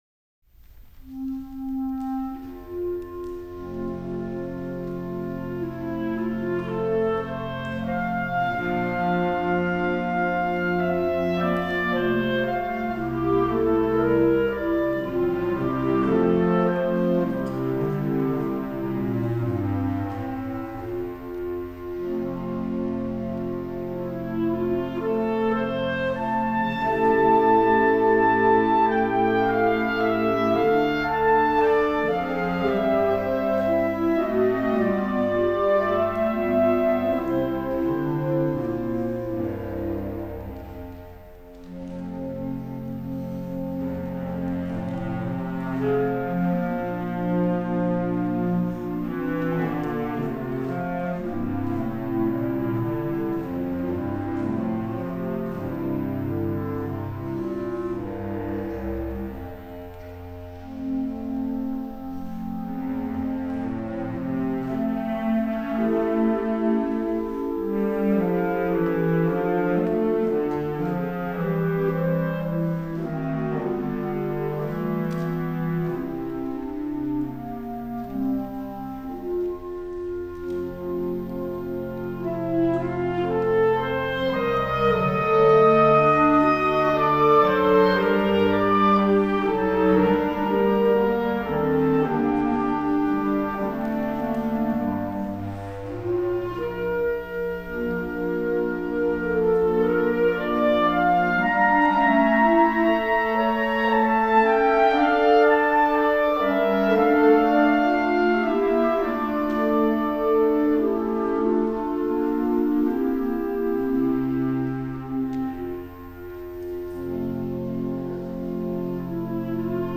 長野市を中心にクラリネット好きが集まって活動している団体です。
高音域のE♭クラリネットから低音のコントラバス・クラリネットまで多種多様なクラリネットを用い、他では味わえない独特のサウンドを楽しんでいます。